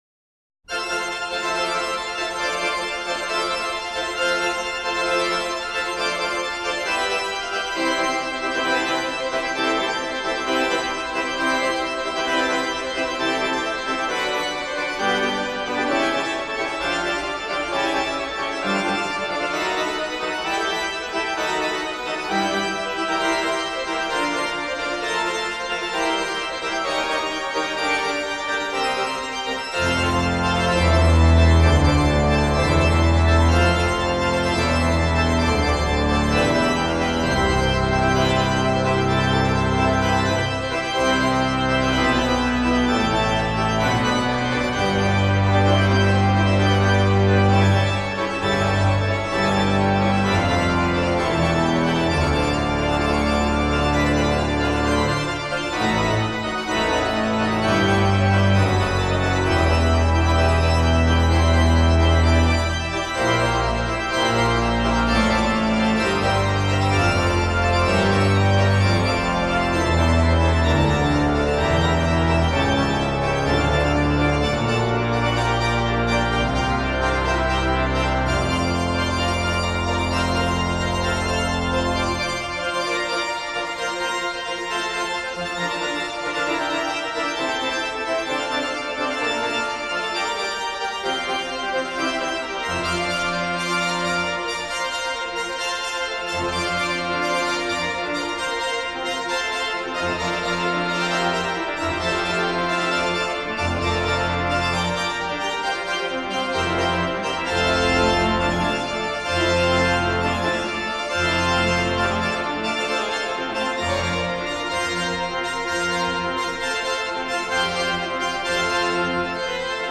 Organists
repertoire samples